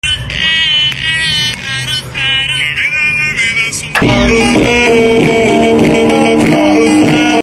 Trying Our Speaker In Motorway Sound Effects Free Download